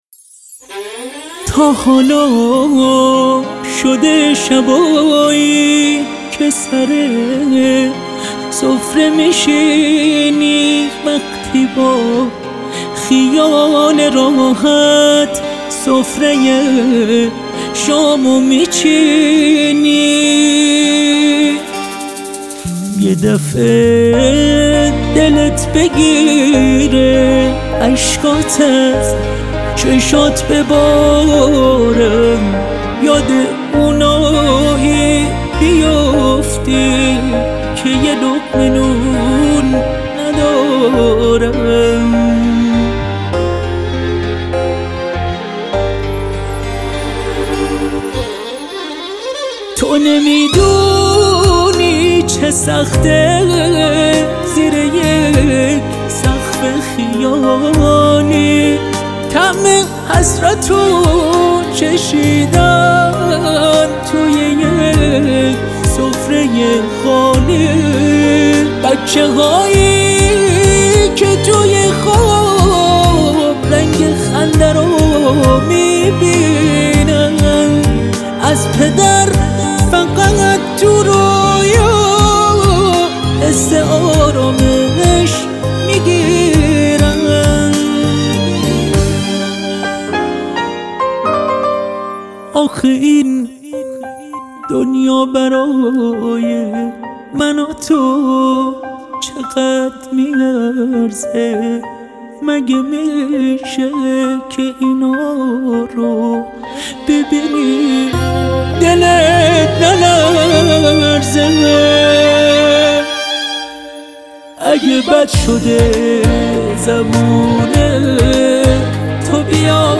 ویولون سولو